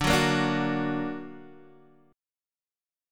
D+ Chord
Listen to D+ strummed